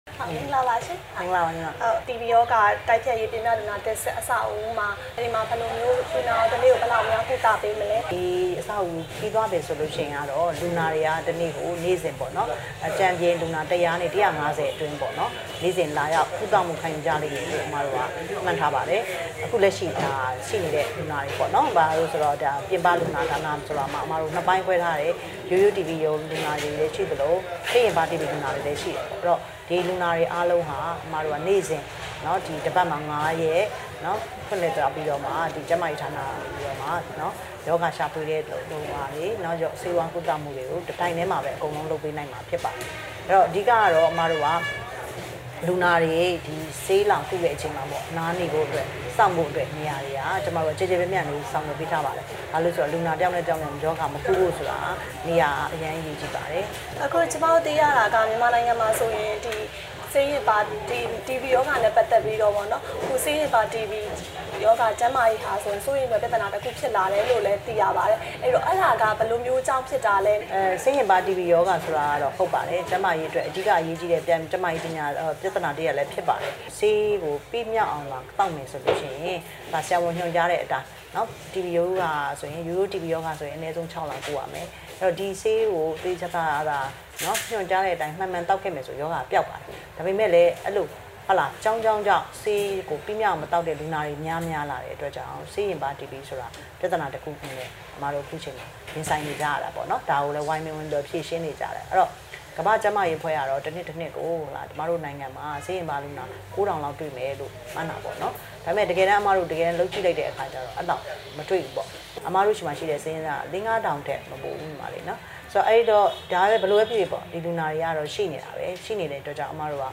တီဘီရောဂါတိုက်ဖျက်ရေးအကြောင်း မေးမြန်းချက်